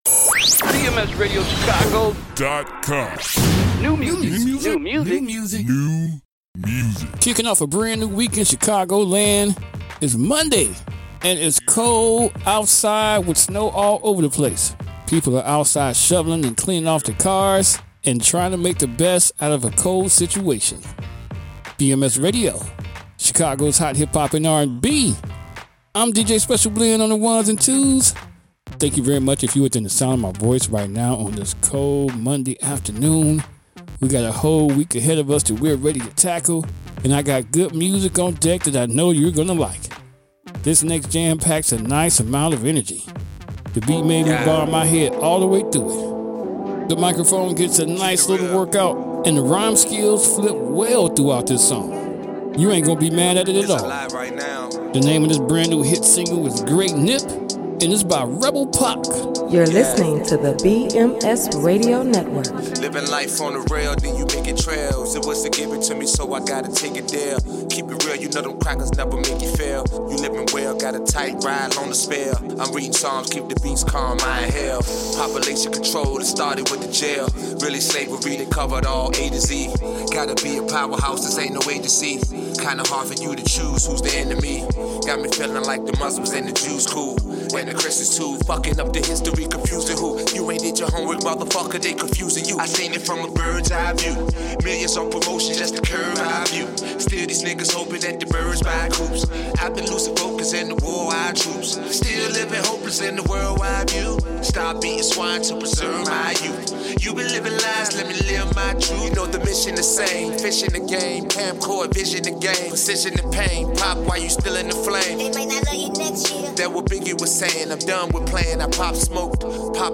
Chicago’s Hot Hip-Hop and R&B.